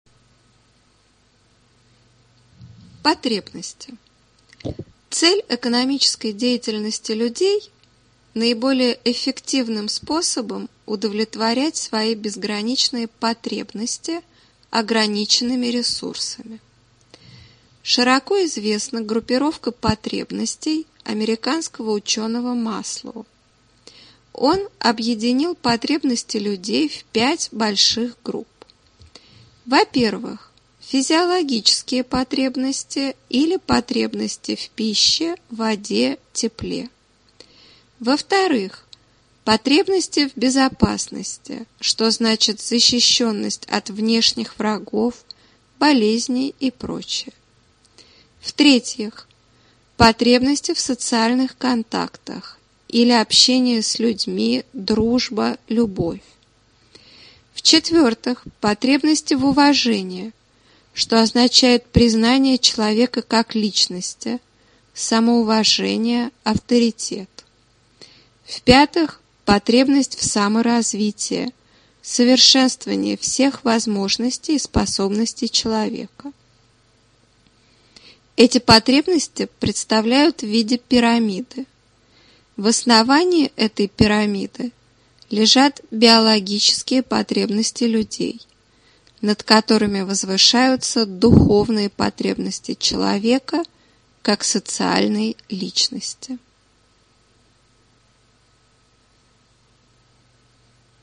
Аудиокнига Выпуск 9. Экономическая теория | Библиотека аудиокниг